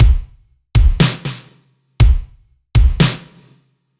Index of /musicradar/french-house-chillout-samples/120bpm/Beats
FHC_BeatC_120-01_KickSnare.wav